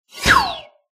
Laser2.ogg